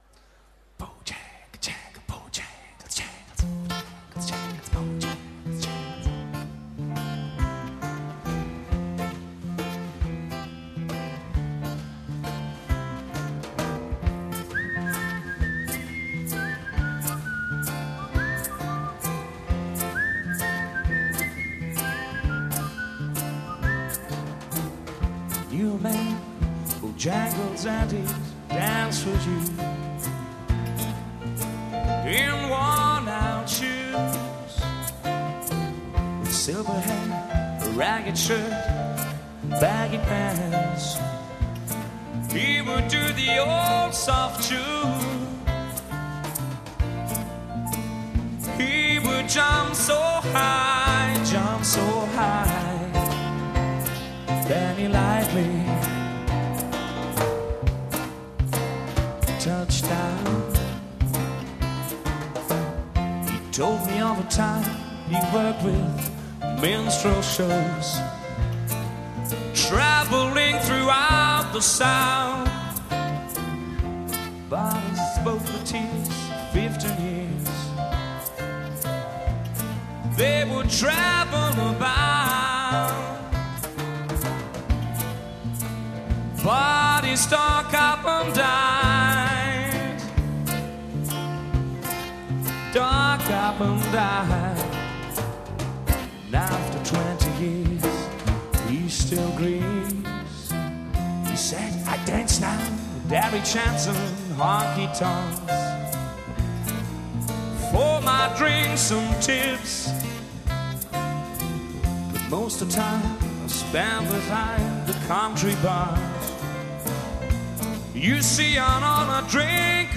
12-köpfige Liveband
sowie der vierstimmige Bläsersatz
Gesang
Schlagzeug
Perkussion
Gitarre
Bass
Keyboards
Posaune
Saxofon